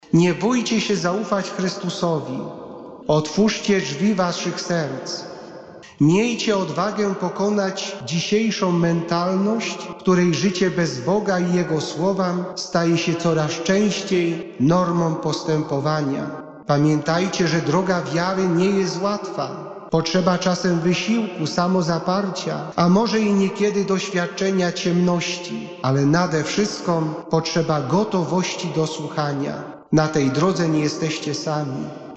W stołecznej bazylice św. Krzyża modlono się w intencji osób poświęconych Bogu.